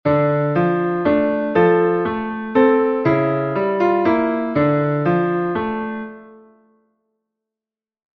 Doch die harmonische Bewegung erzählt jetzt eine ganz andere Geschichte als vorher:
Scarborough Fare mit anderen Akkorden